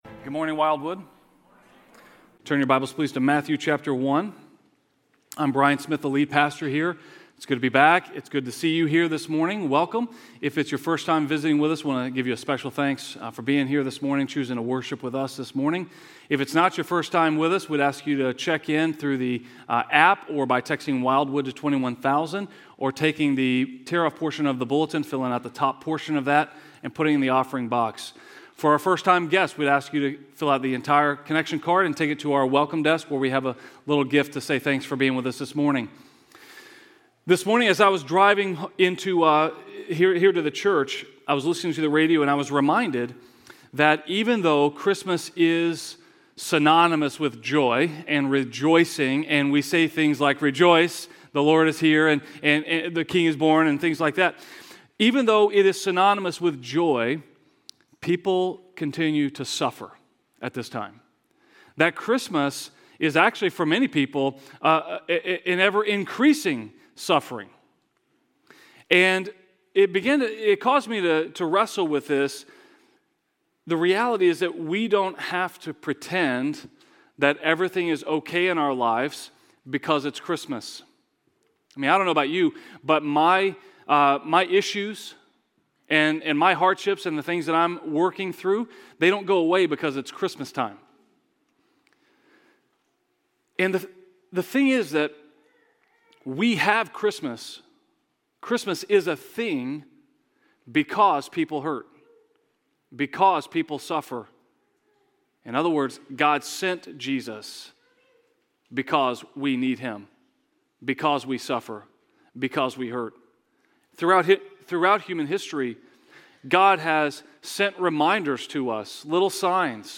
A message from the series "Promise of Christmas."